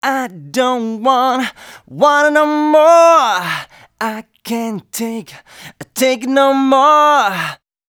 016 male.wav